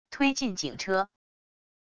推进警车wav音频